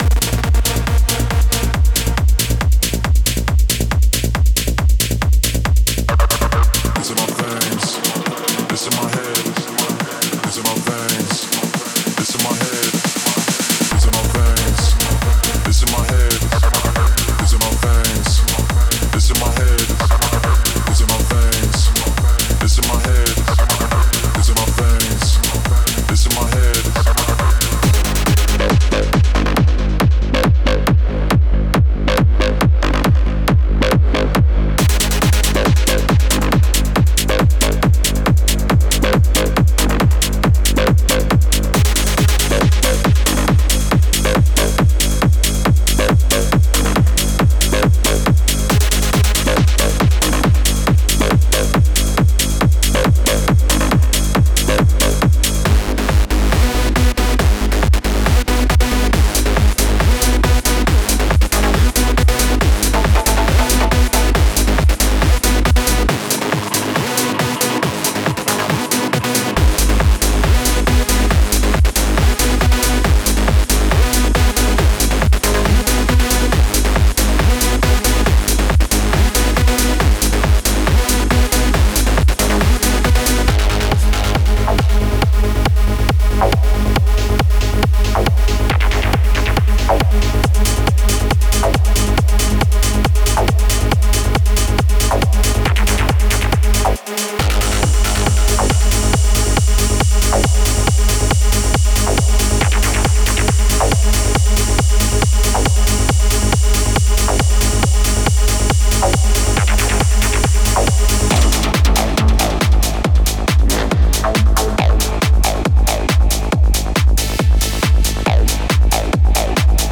デモサウンドはコチラ↓
Genre:Techno
30 Full Drum Loops 138 Bpm
12 Acid Loops 138 Bpm
06 Vocal Loops 138 Bpm